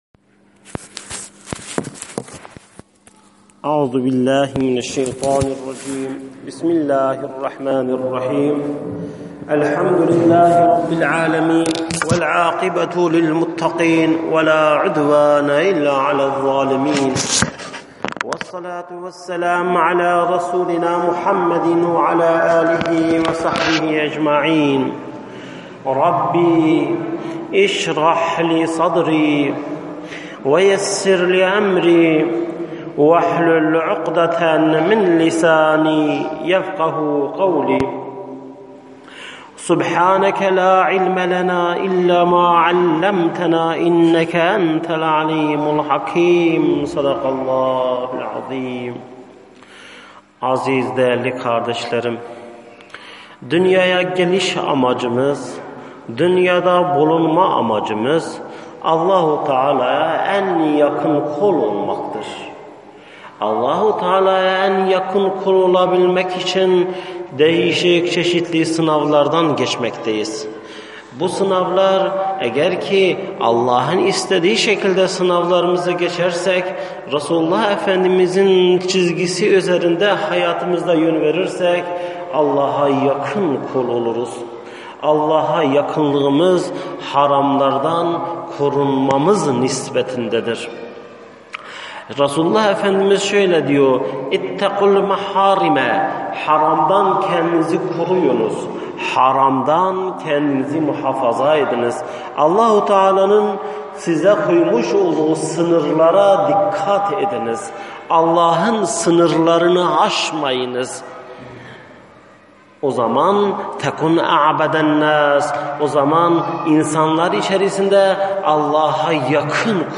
SESLİ SOHBETLER